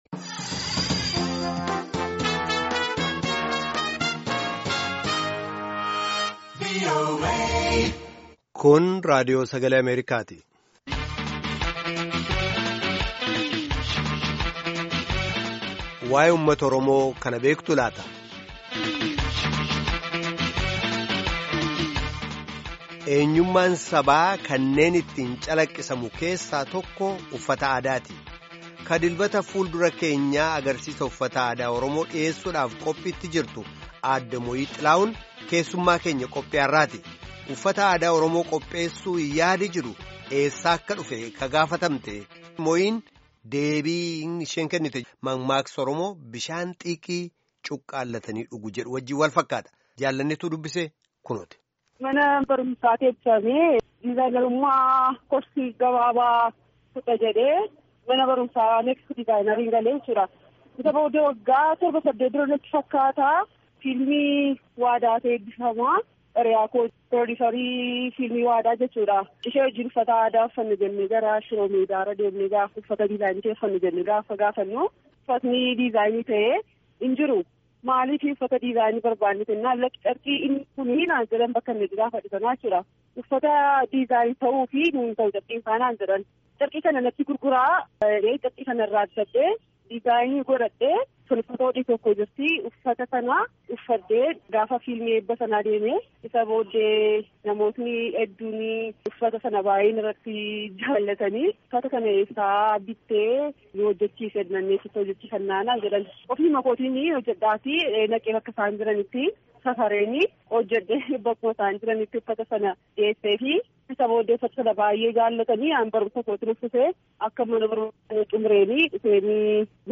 Gaaffii fi deebii gaggeeffame caqasaa.